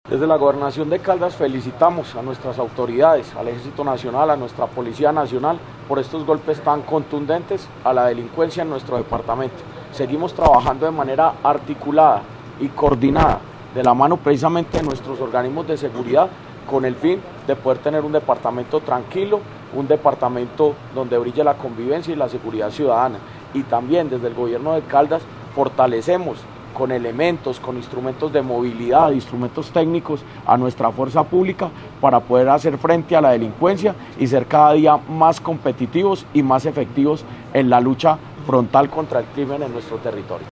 Jorge Andrés Gómez Escudero, secretario de Gobierno de Caldas
Jorge-Andres-Gomez-Escudero-secretario-de-Gobierno-de-Caldas-1-1.mp3